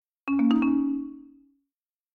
new_message2.mp3